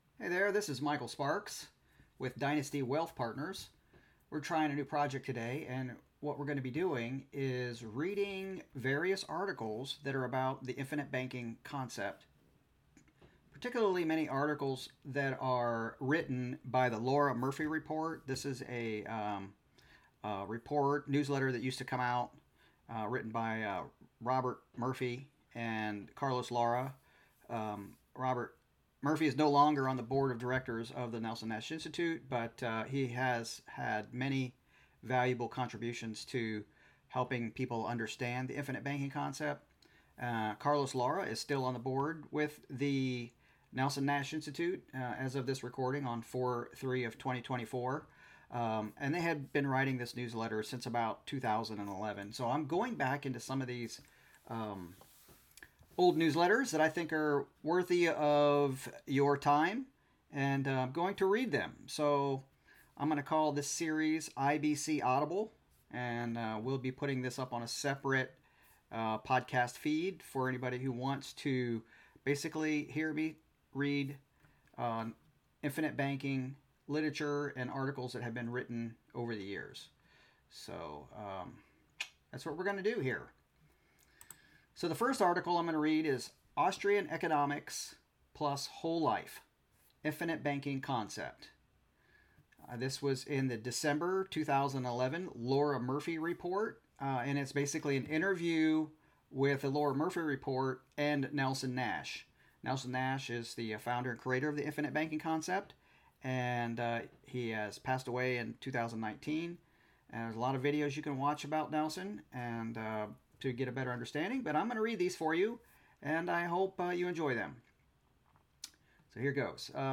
Reading of the Lara-Murphy Report article entitled AUSTRIAN ECONOMICS + WHOLE LIFE = IBC